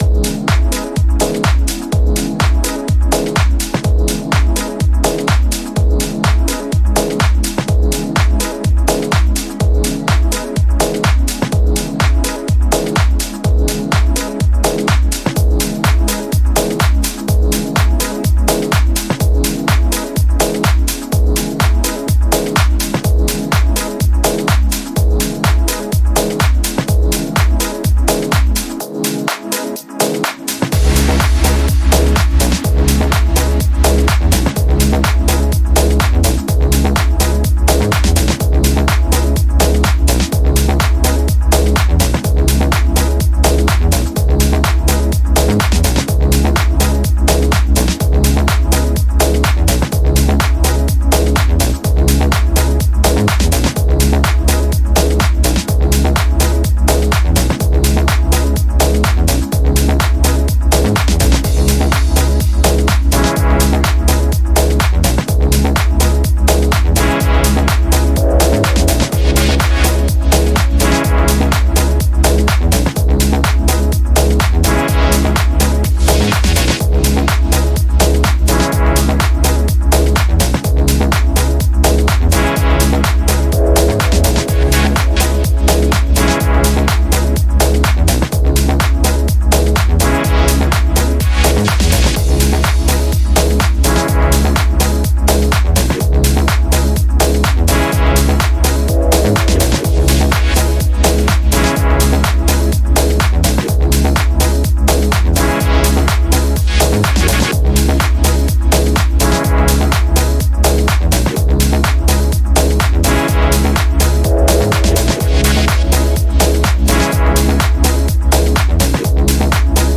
Genre: Minimal / Deep Tech